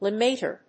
/lɪˈmetɝ(米国英語), lɪˈmeɪtɜ:(英国英語)/